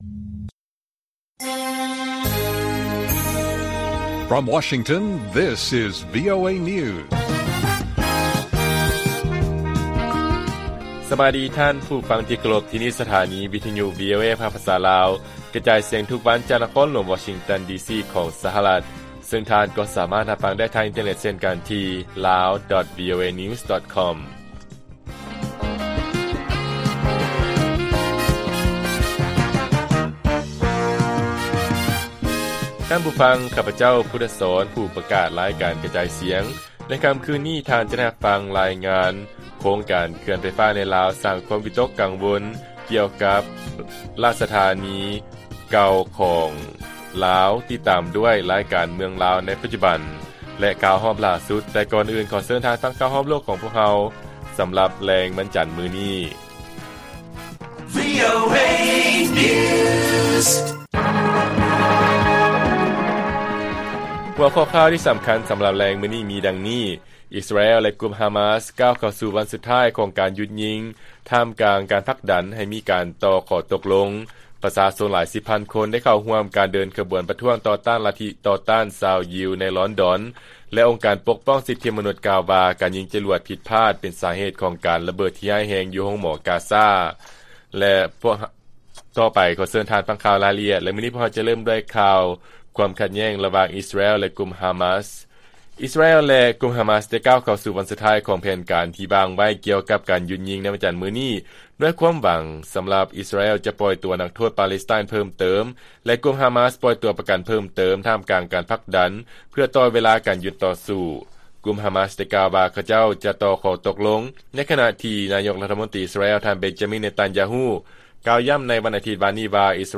ລາຍການກະຈາຍສຽງຂອງວີໂອເອ ລາວ: ອິສຣາແອລ ແລະ ກຸ່ມຮາມາສ ກ້າວເຂົ້າສູ່ວັນສຸດທ້າຍ ຂອງການຢຸດຍິງ ທ່າມກາງການຜັກດັນ ໃຫ້ມີການຕໍ່ຂໍ້ຕົກລົງ